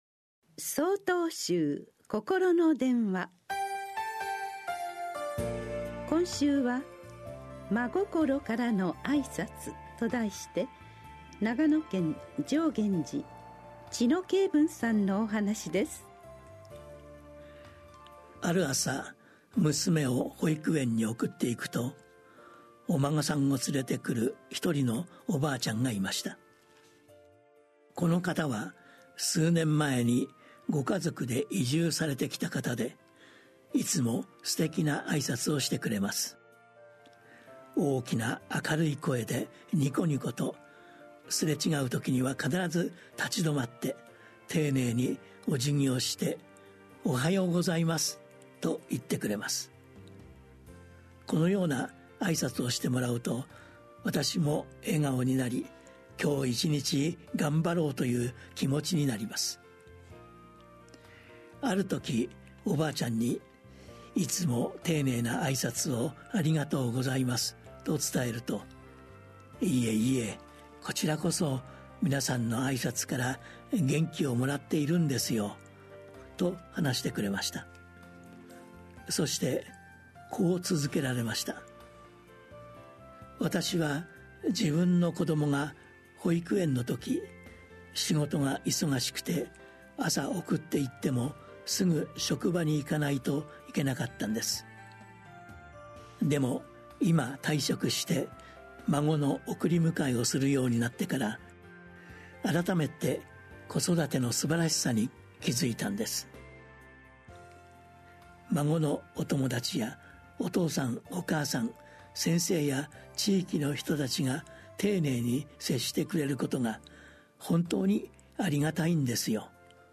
心の電話（テレホン法話）７/８公開『まごころからの挨拶』 | 曹洞宗 曹洞禅ネット SOTOZEN-NET 公式ページ